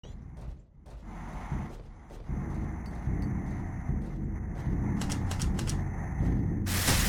A-120_jumpscare.mp3